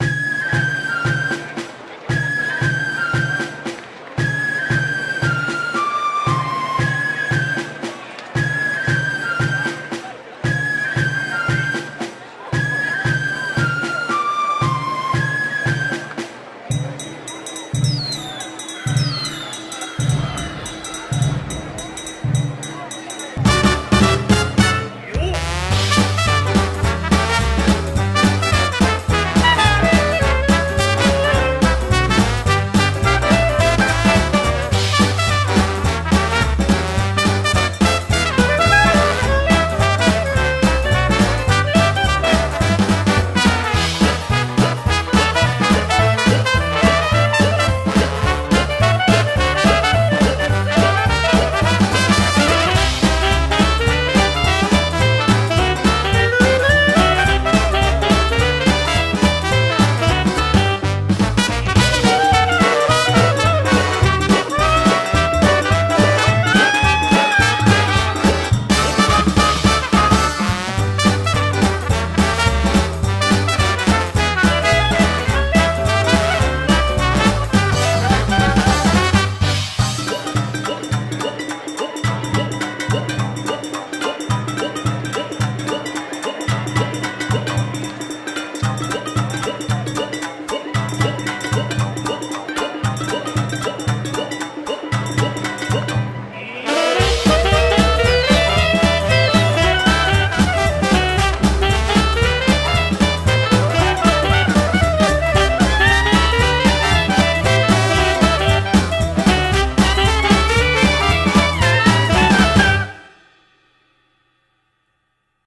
BPM115-230
Audio QualityMusic Cut